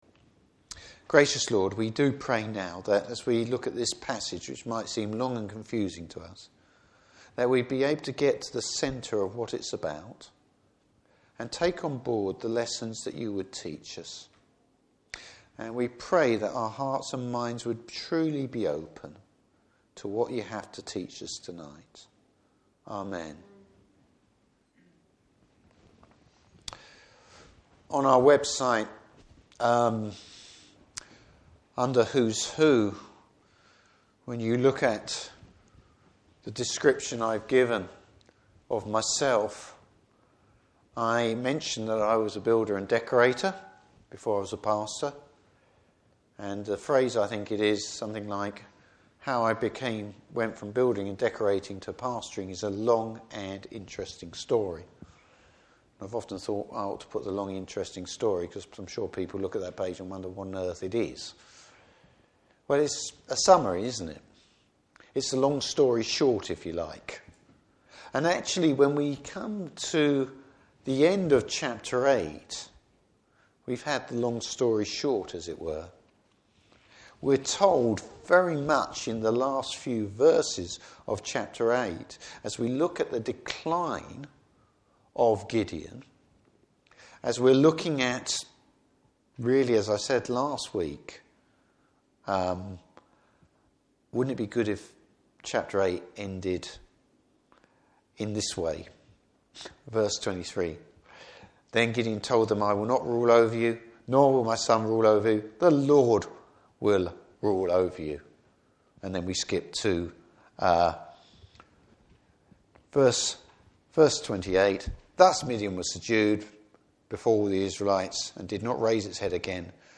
Service Type: Evening Service Bible Text: Judges 9.